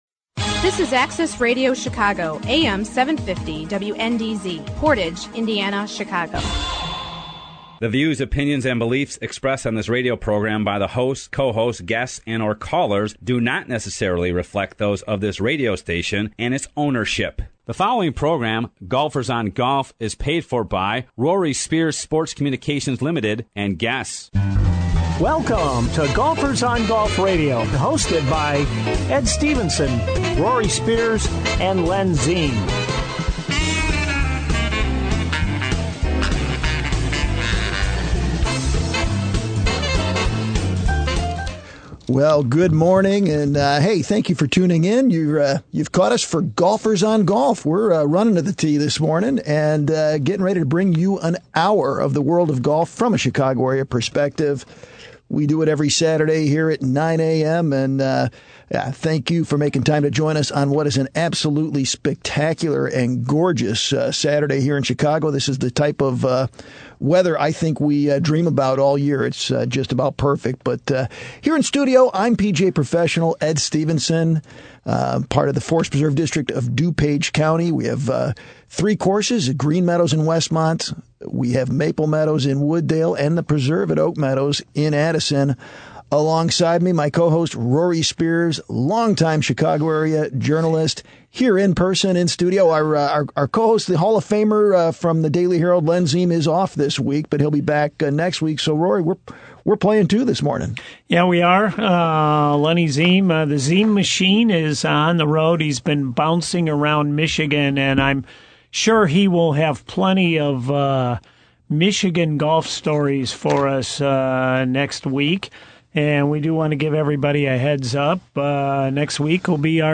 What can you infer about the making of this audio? Golfers on Golf Radio Week 14 hits the airwaves today on Am 750 WNDZ-Chicago.